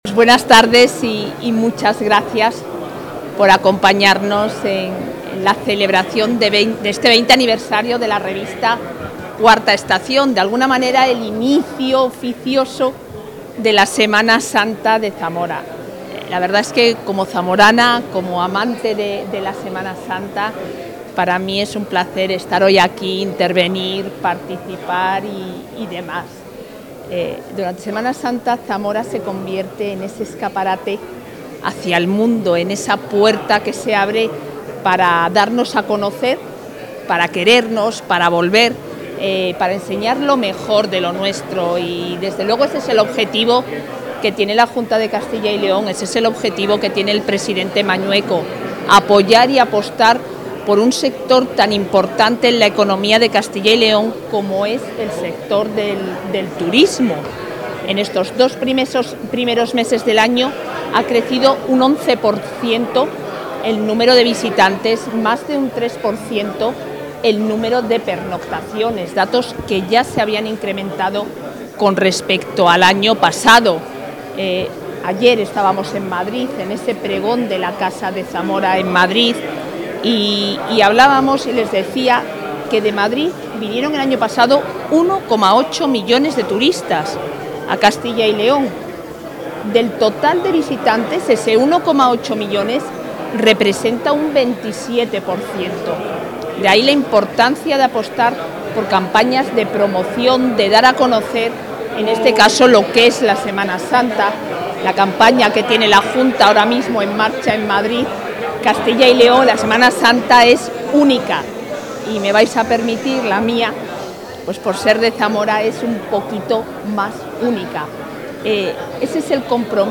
Intervención de la vicepresidenta.
La vicepresidenta de la Junta de Castilla y León y consejera de Familia e Igualdad de Oportunidades, Isabel Blanco, ha sido la encargada de clausurar el acto en el que la publicación ‘IV Estación’, especializada en la Semana Santa de Zamora, ha celebrado su vigésimo aniversario.